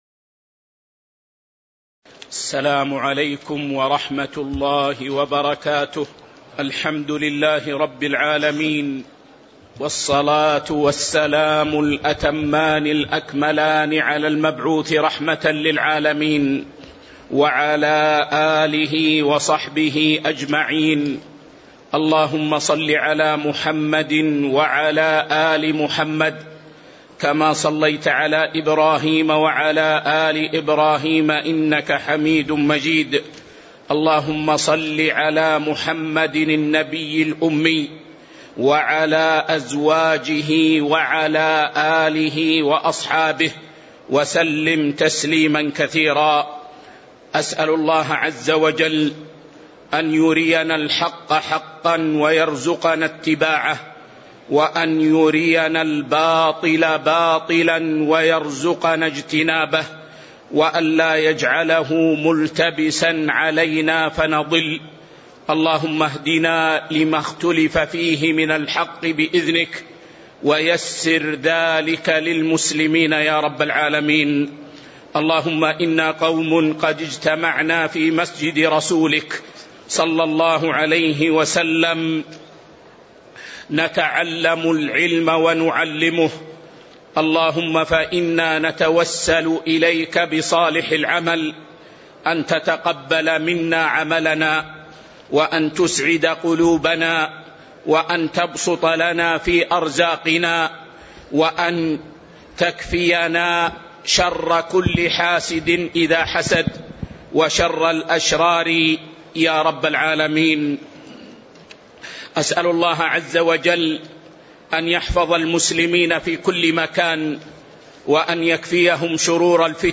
تاريخ النشر ١٦ صفر ١٤٤٥ هـ المكان: المسجد النبوي الشيخ